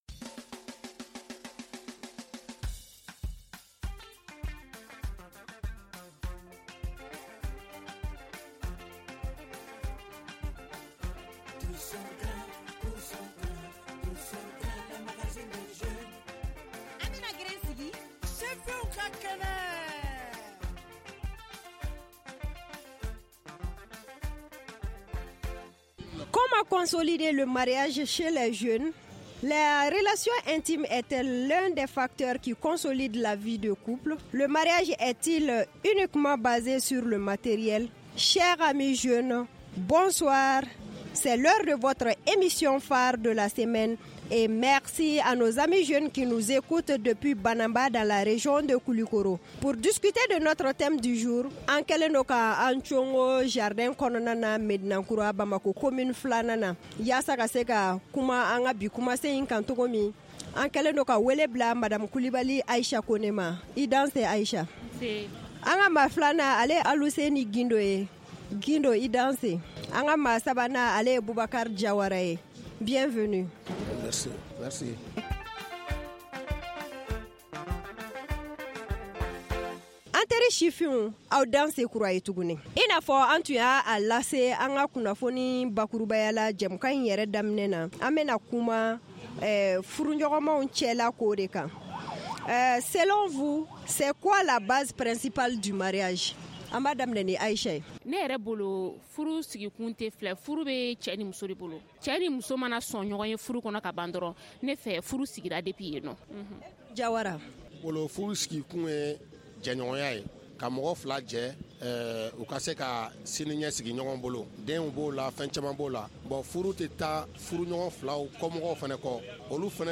Le tous au grin de cette semaine pose le débat à Médine en commune II du district de Bamako.